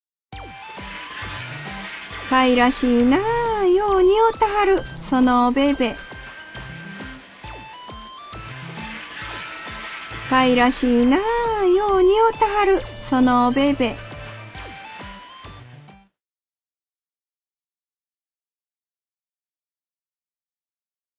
• 読み上げ
市田ひろみ 語り●市田ひろみ